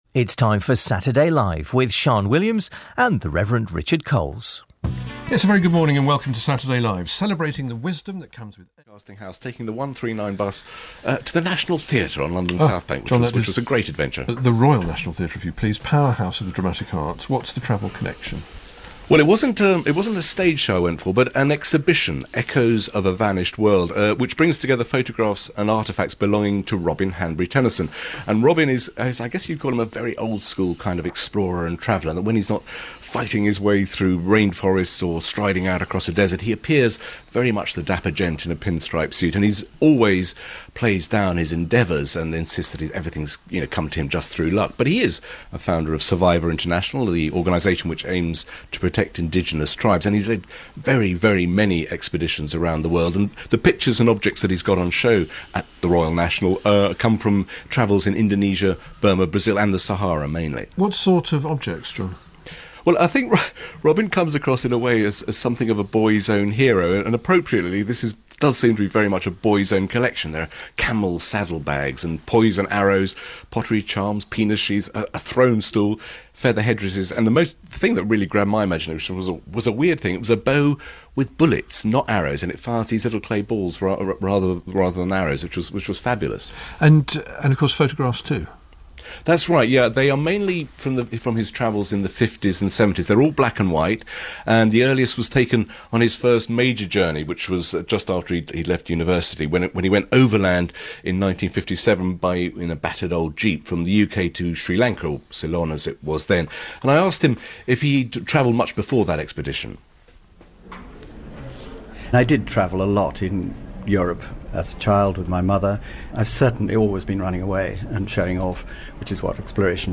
Radio Interview with Robin Hanbury-Tenison
Robin was interviewed on BBC Radio 4's Saturday Live, 26 January 1013, at 9:00am.
Robin Saturday Live BBC Radio 4.mp3